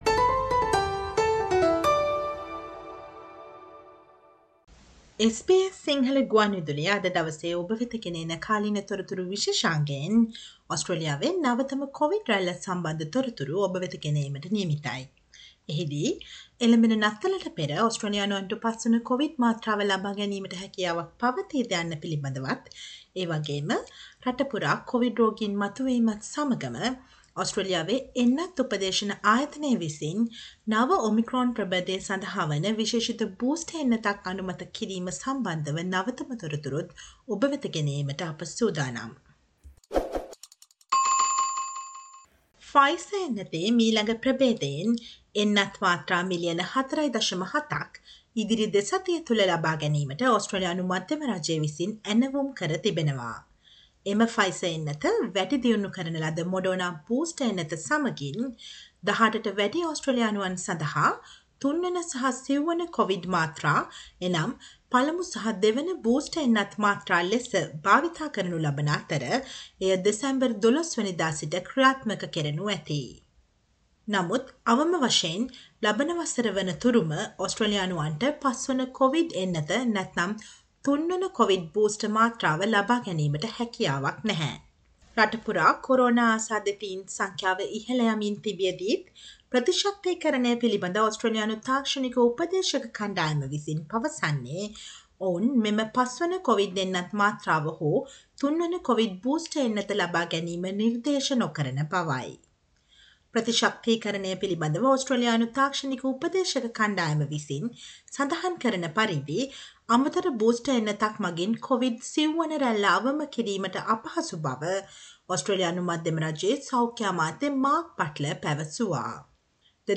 නොවැම්බර් 17 වෙනි බ්‍රහස්පතින්දා ප්‍රචාරය වූ SBS සිංහල ගුවන් විදුලි සේවයේ කාලීන තොරතුරු විශේෂාංගයට සවන් දෙන්න.